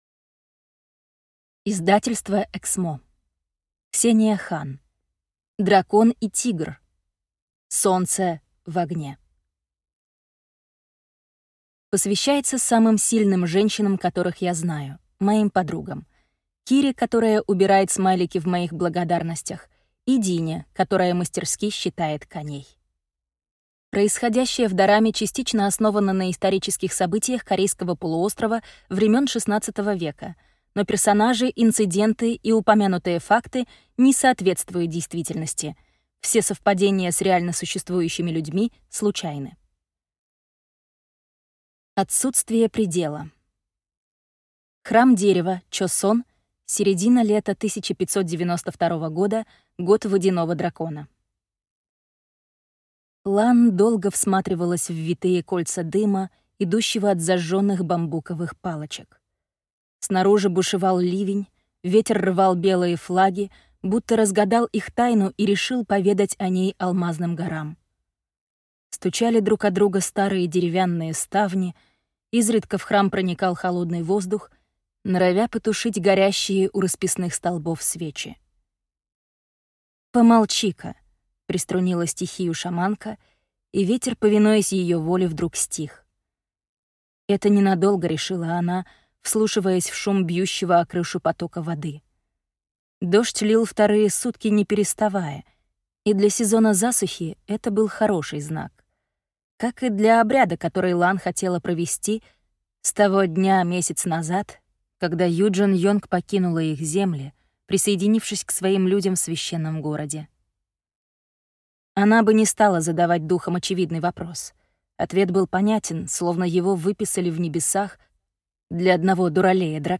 Аудиокнига Солнце в огне | Библиотека аудиокниг